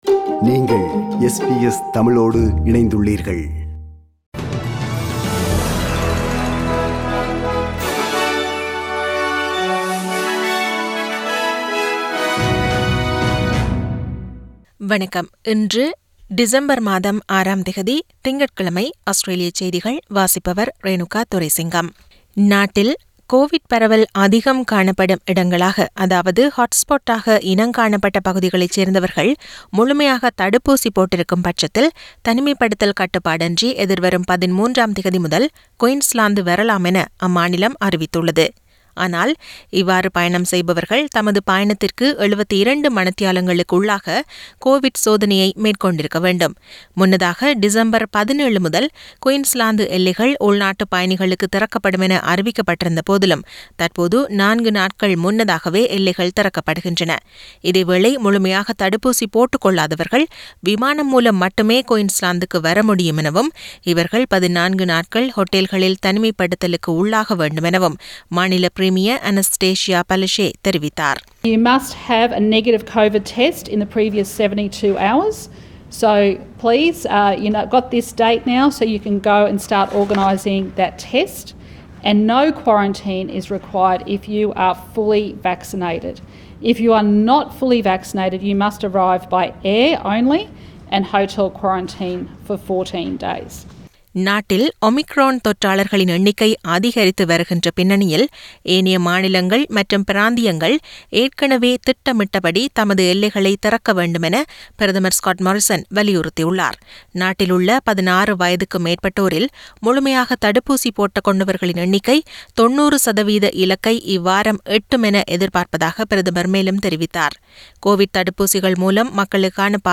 Australian news bulletin for Monday 06 December 2021.